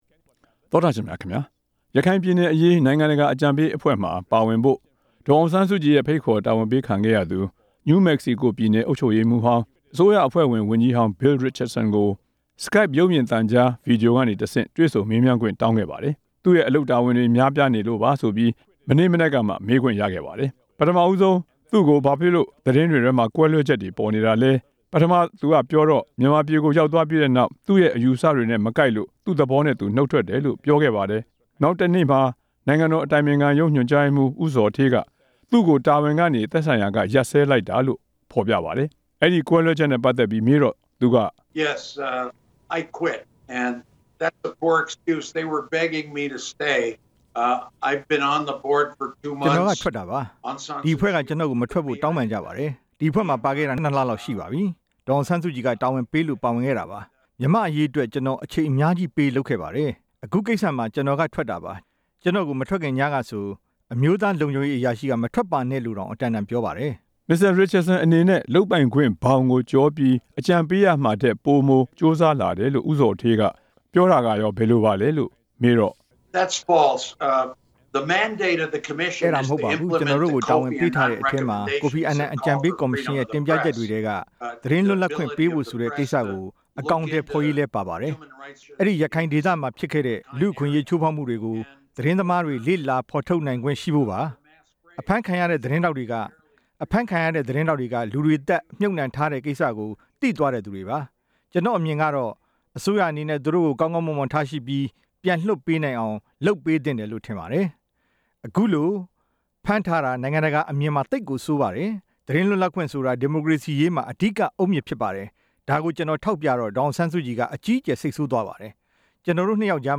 ကွန်ပြူတာ Skype အစီအစဉ်ကတဆင့် RFA နဲ့ ဗီဒီယို ဆက်သွယ်မေးမြန်းချက်မှာ သူက အခုလို ပြောသွားတာပါ။ အဲဒီအဖွဲ့က သူ နုတ်ထွက်ခဲ့တာဖြစ်ကြောင်း နောက်တစ်နေ့ အစိုးရ ကြေညာသလို သူအထုတ်ခံရတာ မဟုတ်ကြောင်း၊ အခုအခါ ရိုက်တာသတင်းထောက်တွေကို ဖမ်းဆီးထားတာ နိုင်ငံတကာအမြင်မှာ သိပ်ကိုဆိုးတဲ့ အတွက် သူတို့ကိုပြန်လွတ်ပေးနိုင်အောင် အစိုးရက လုပ်ပေးသင့်ကြောင်းလည်း ပြောပါတယ်။